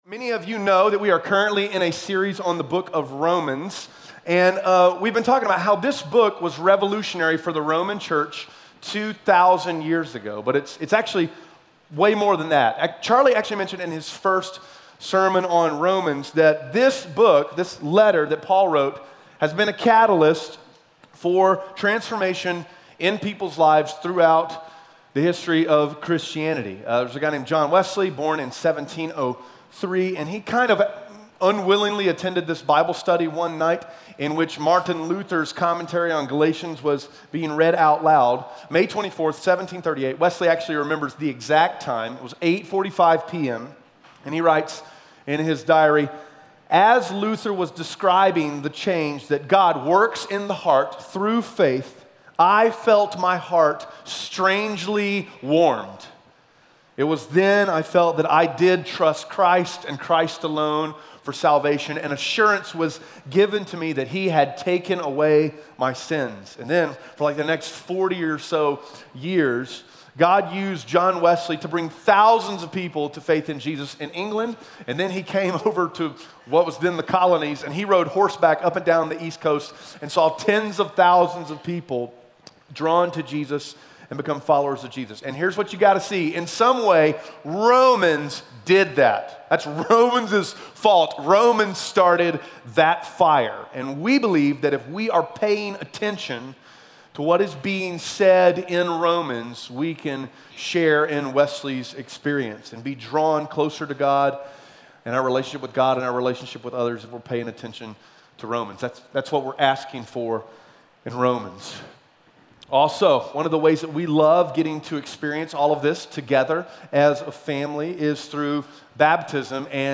Romans 3:9-20 Audio Sermon Notes (PDF) Onscreen Notes Ask a Question *We are a church located in Greenville, South Carolina.